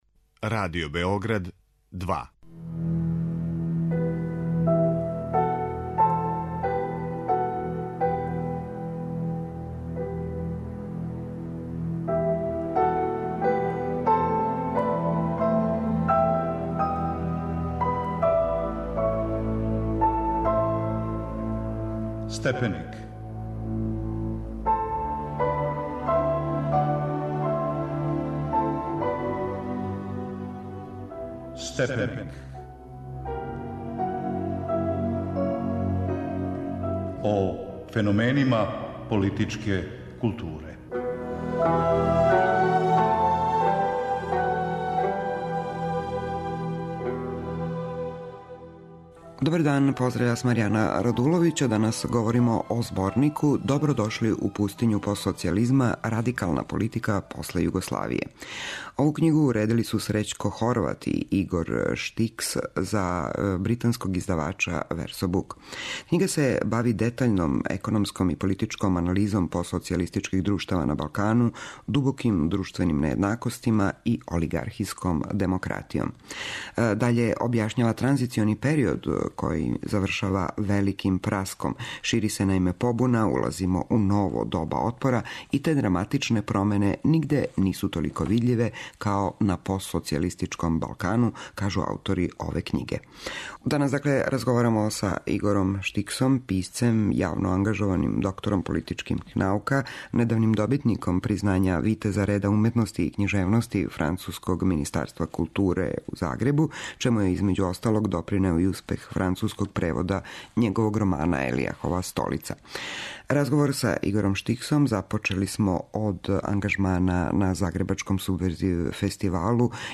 Гост емисије је Игор Штикс